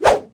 handswing5.ogg